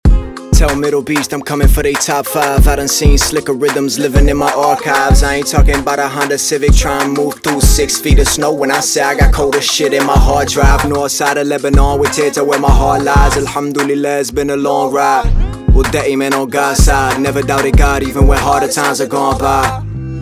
Obtenez un son vocal propre, clair et puissant directement dans Ableton Live grâce à ce rack d’effets prêt-à-l’emploi.